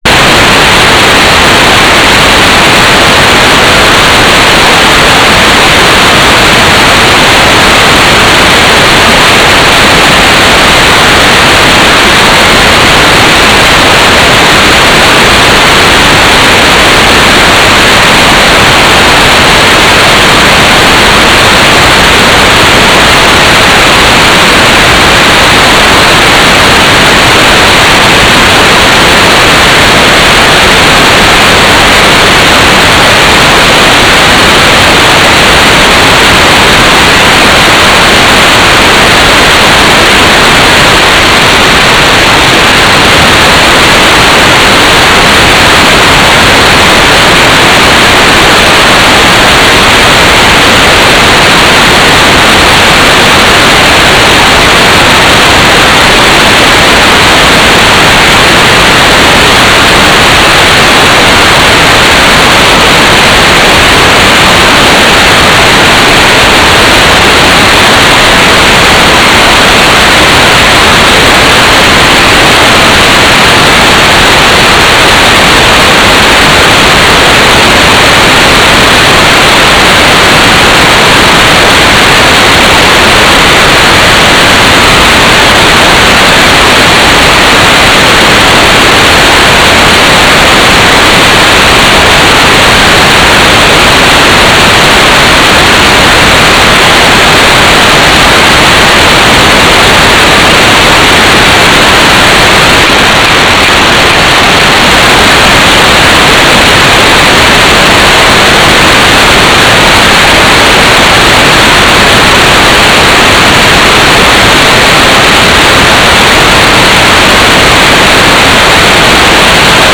"transmitter_description": "Mode U - GMSK 4k8 AX.25 TLM",
"transmitter_mode": "GMSK",